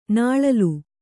♪ nāḷalu